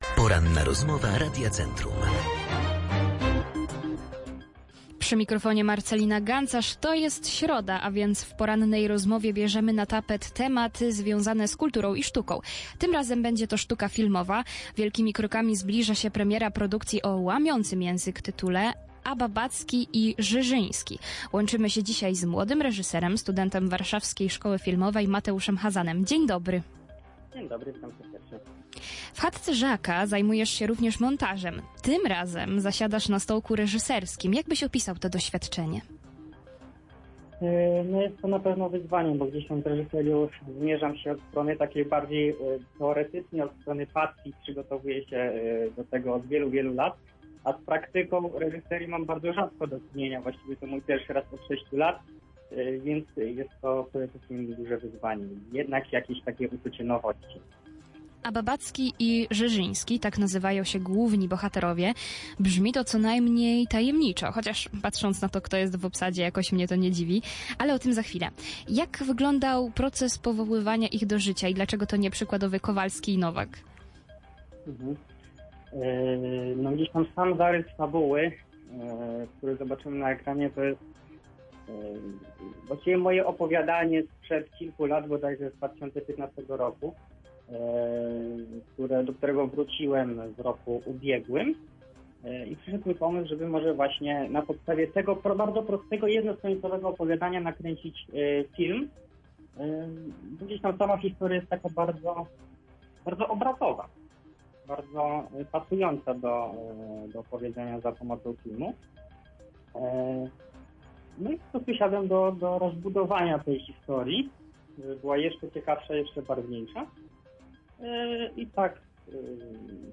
Opublikowano w Aktualności, Kultura, Poranna Rozmowa Radia Centrum